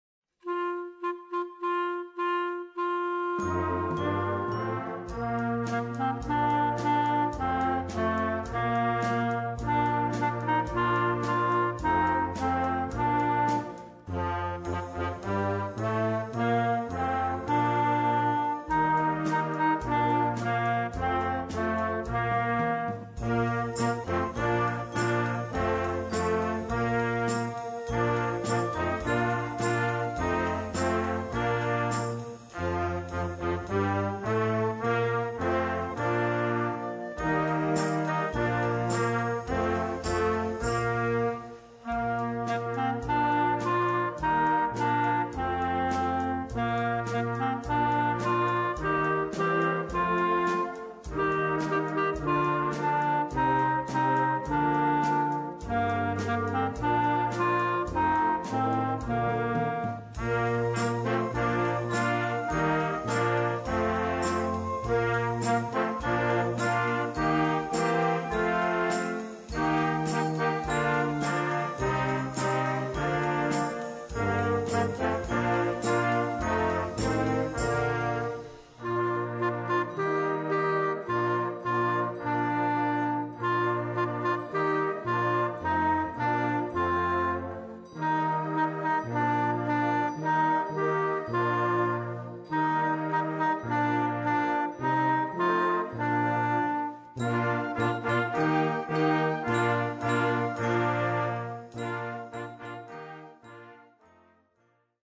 Gattung: Solo für Klarinette und Jugendblasorchester
Besetzung: Blasorchester